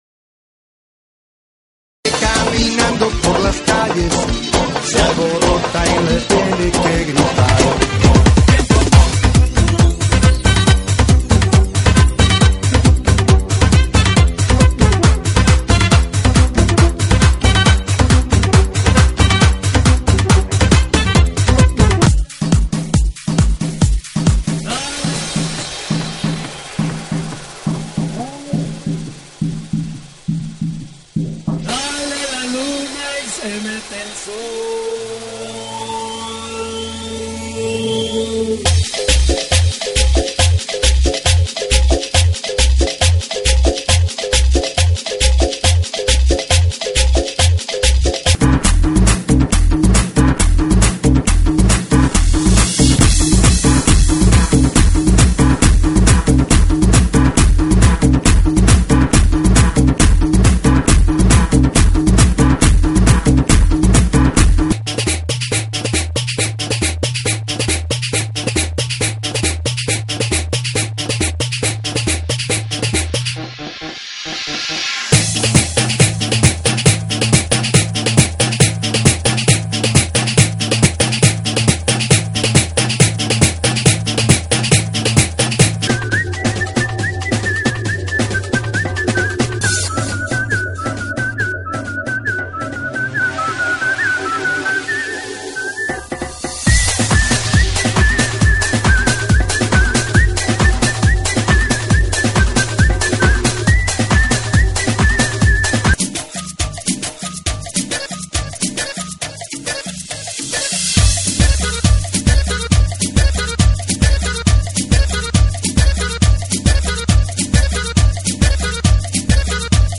GENERO: REGGAETON – LATINO – TRIBAL